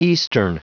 Prononciation du mot eastern en anglais (fichier audio)
Prononciation du mot : eastern